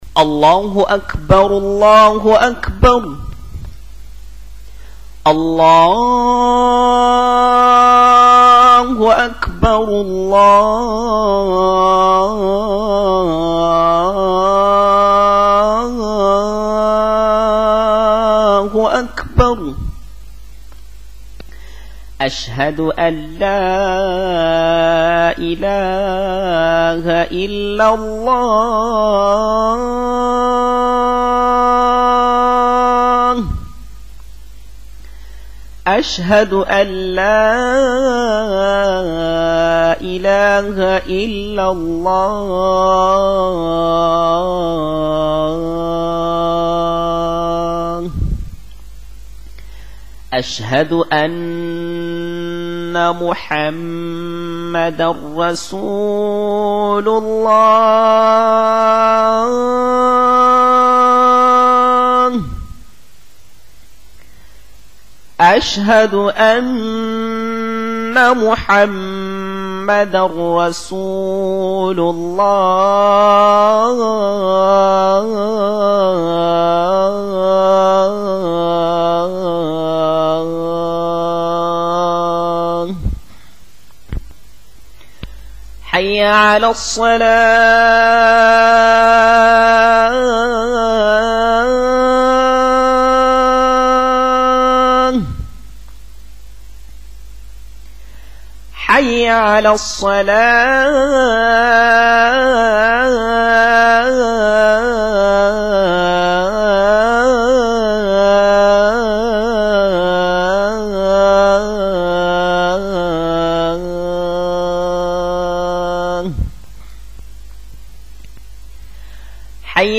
ADHAAN - CALL TO PRAYER
When the time for any of the 5 obligatory prayers comes, a man (called a mu-adh-dhin) and calls aloud these words to summon muslims in the neighbourhood of the mosque to come to prayer: